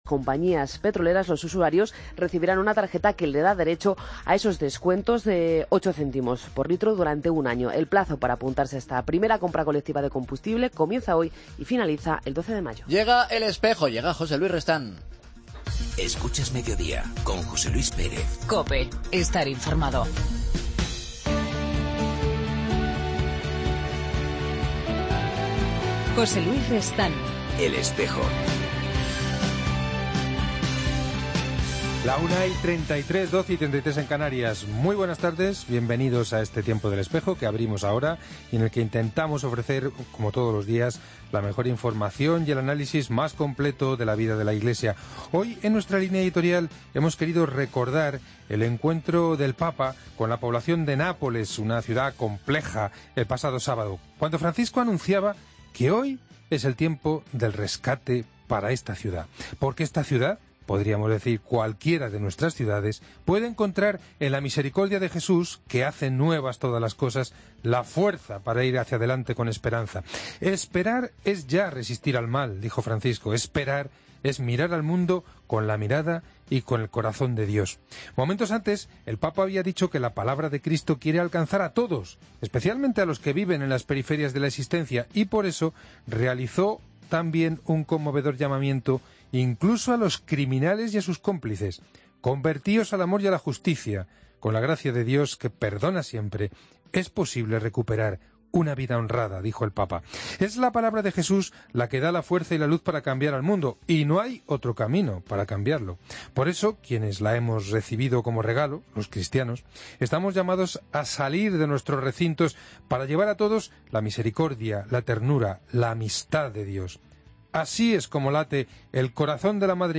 Hoy hemos hablado de la presencia de la Iglesia en Europa, a raíz de la reciente Asamblea de la COMECE, Comisión de los Episcopados de la Unión Europea. Para ello, ha estado con nosotros el representante de la CEE, Mons. Adolfo González Montes, obispo de Almería.